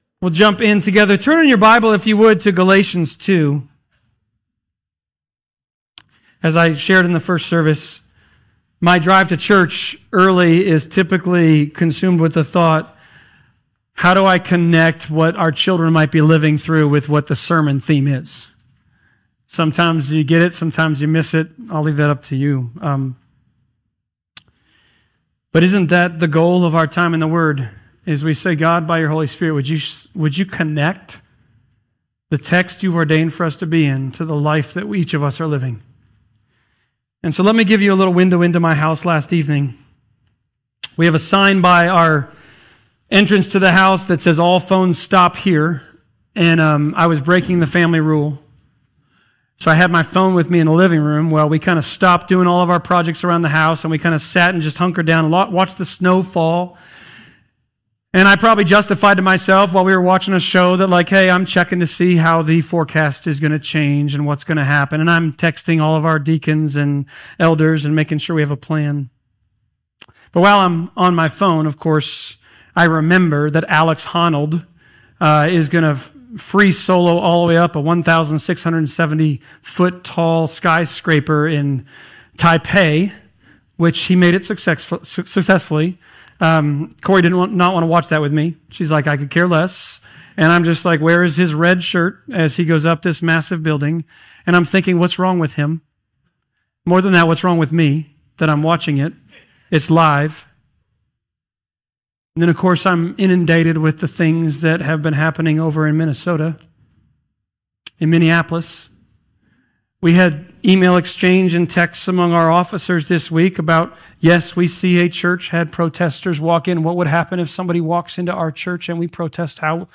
Galatians Passage: Galatians 2.1-14 Service Type: Sermons « Christ’s Gospel Christ Community Church